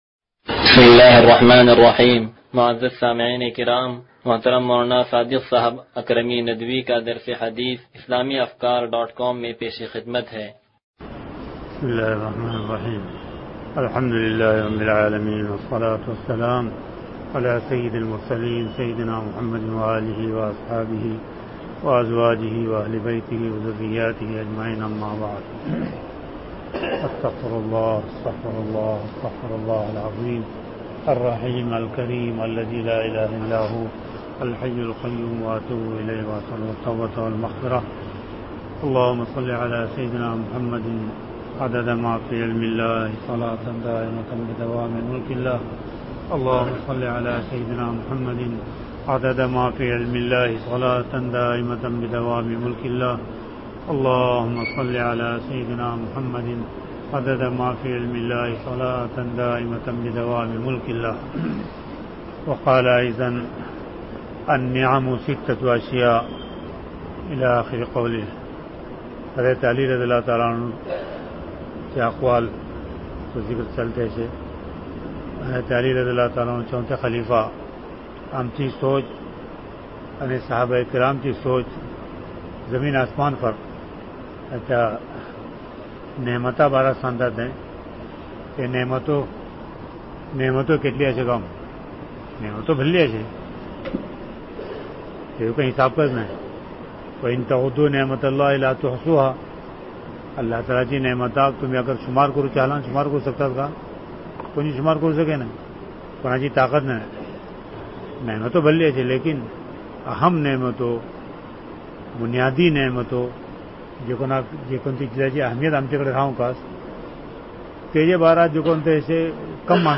درس حدیث نمبر 0157